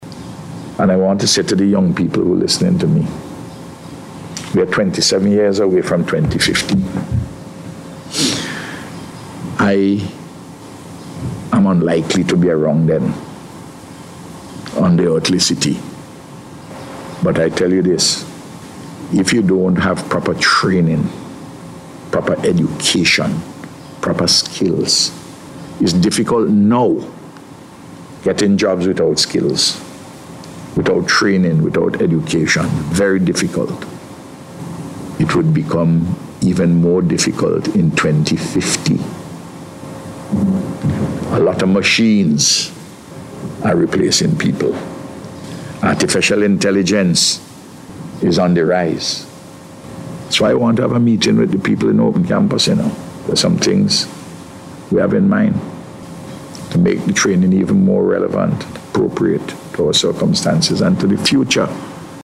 This was confirmed by Prime Minister Dr. Ralph Gonsalves during a News Conference on Thursday.